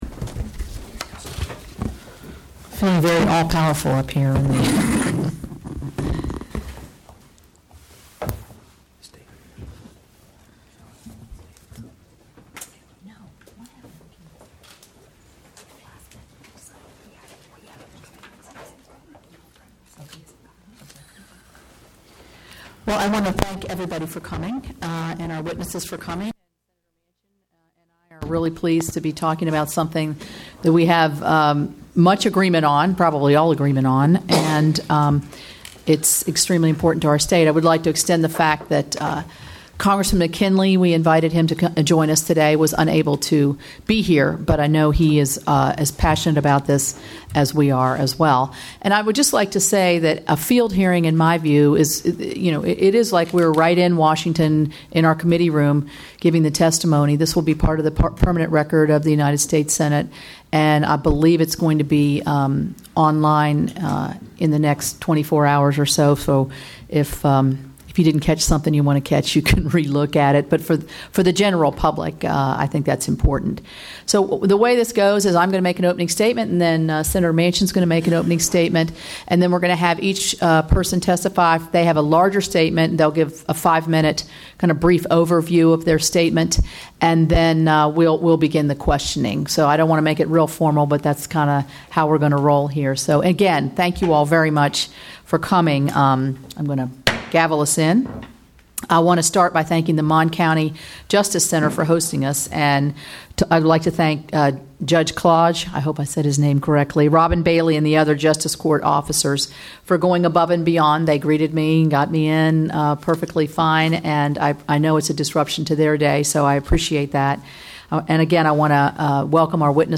The hearing was held on Monday, August 29, 2016 at 2:00 pm EDT at the Monongalia County Justice Center in Morgantown, West Virginia.
Audio of the hearing and witness testimony is available below.